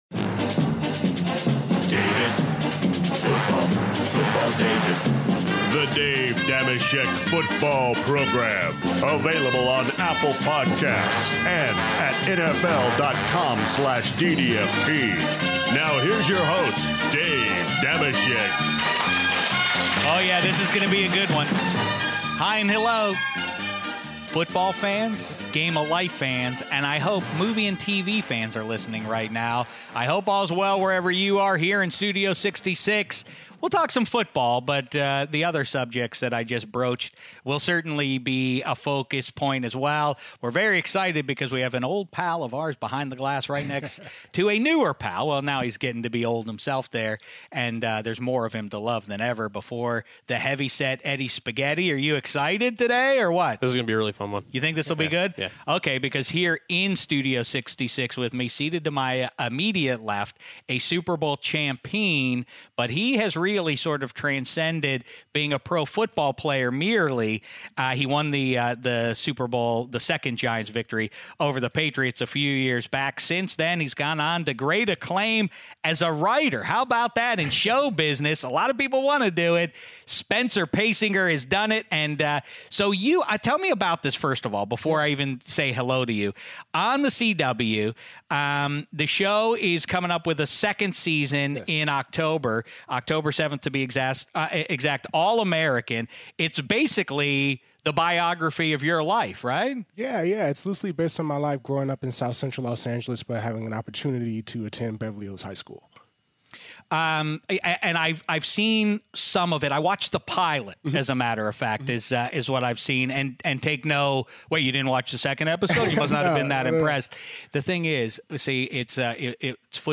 Dave Dameshek is joined in Studio 66 by former NFL linebacker and current Hollywood producer Spencer Paysinger!